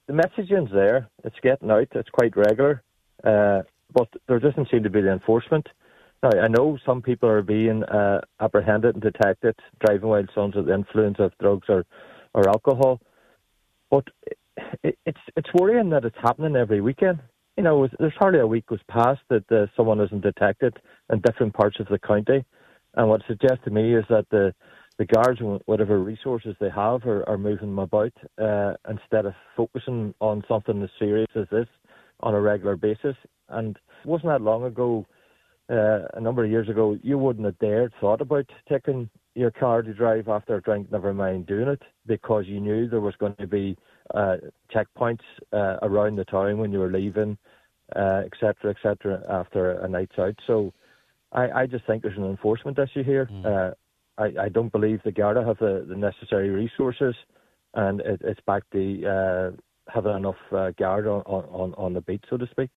Councillor Gerry McMonagle says it once again points to the lack of resources available to Gardai: